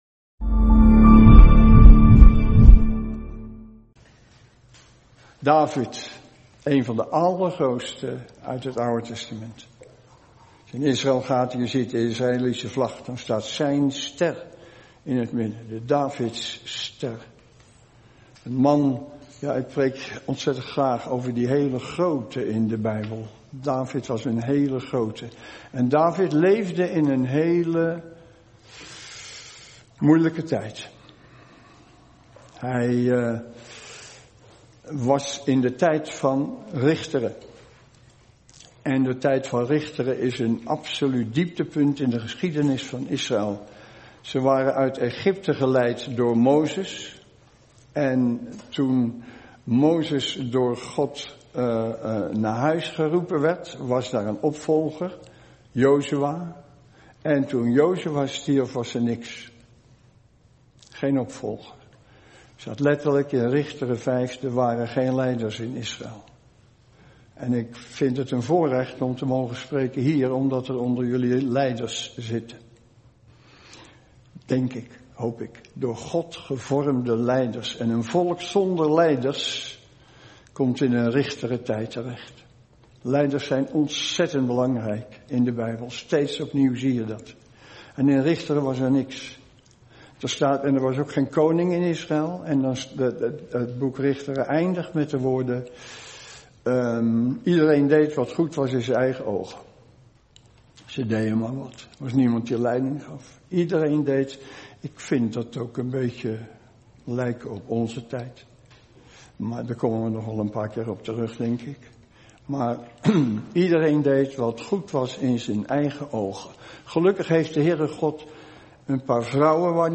Een preek over 'God zoekt mannen en vrouwen naar Zijn hart!'.